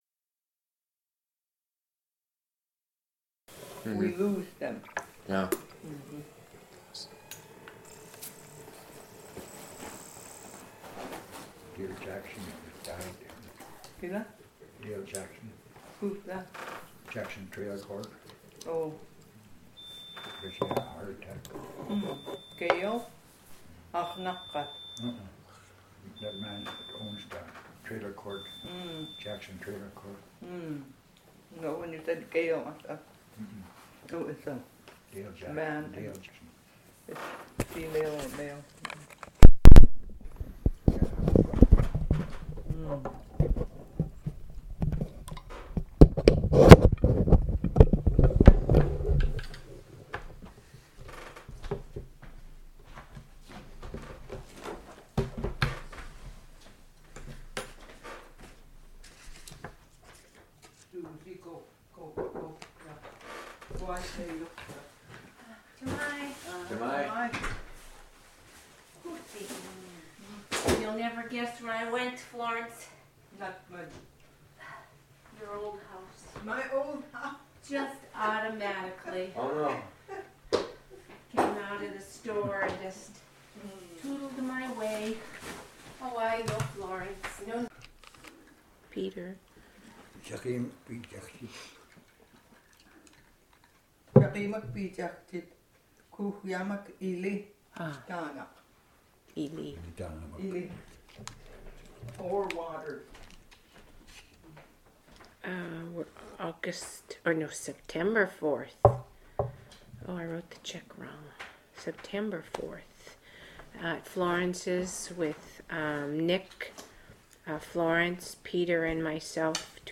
Access audio Summary: different sentence topics Description: different sentence topics Original Format: MiniDisc (AM470:311A) Migration: CD (AM470:311B) Location: Location Description: Kodiak, Alaska